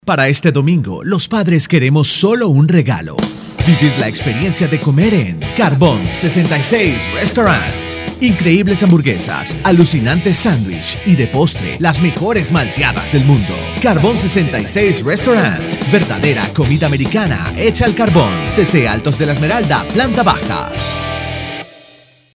Sprechprobe: Werbung (Muttersprache):
Male Spanish voice over, latin voice, young voice, fresh voice, Español, voiceover, locutor, voice acting, dubbing actor, video corporativo, voz masculina, acento neutro, acento venezolano, warm, comforting, powerful, sincere, authentic, fun, relaxed, conversational, GENUINE, FRIENDLY